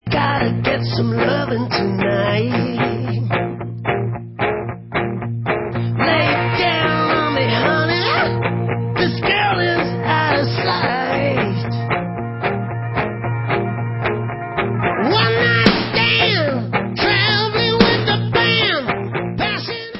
rocková kapela
studiové album